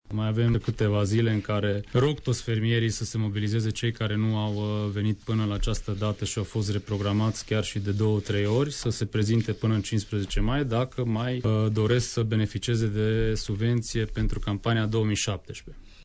Directorul executiv al Agenţiei de Plăţi şi Intervenţie pentru Agricultură (APIA) Mureş, Ovidiu Săvâşcă: